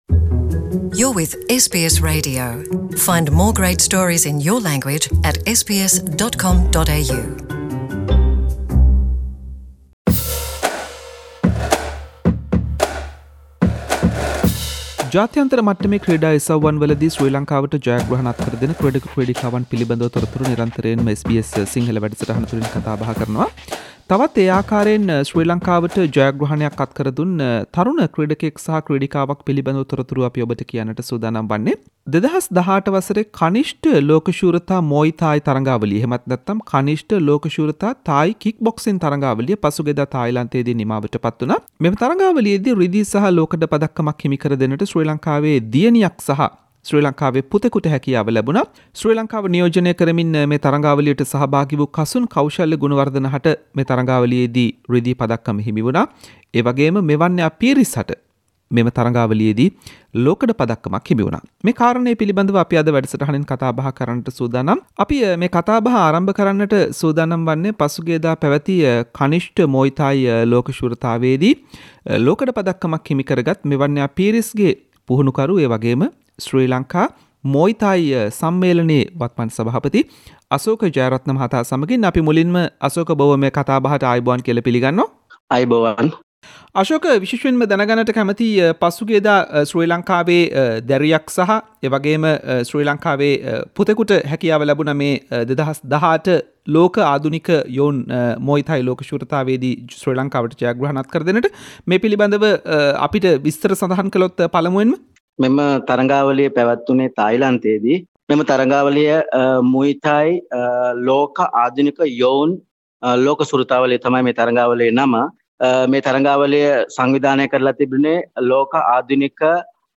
කතා බහක්.